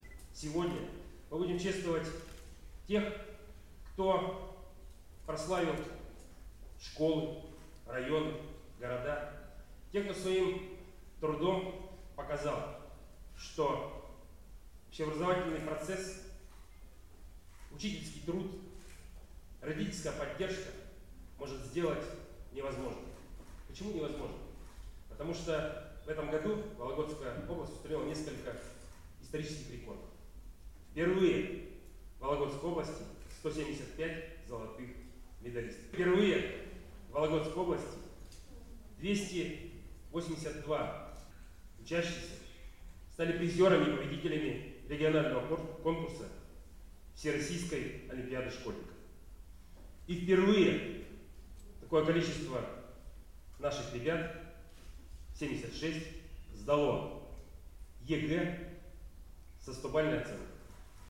Торжественное вручение состоялось во вторник, 25 июня, в зале областной филармонии.
Олег Кувшинников рассказывает о вологодских медалистах